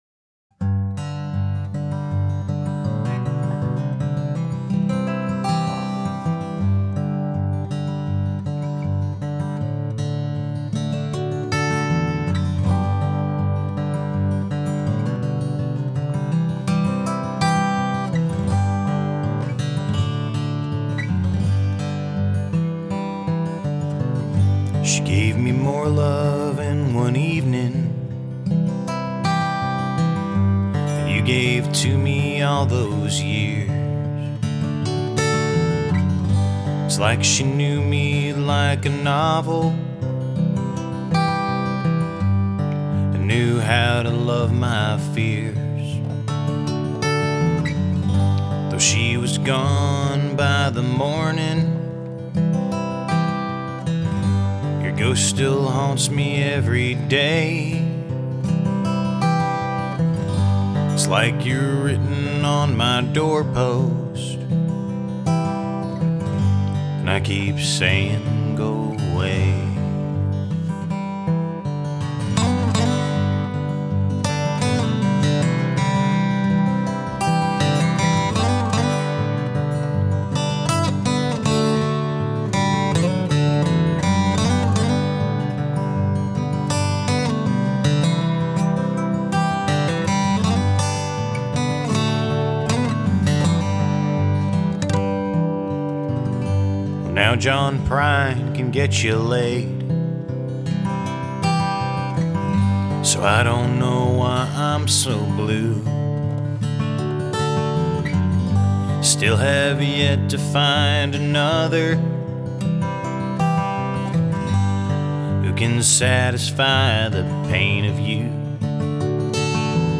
Recorded at home in Hampton Roads, Virginia.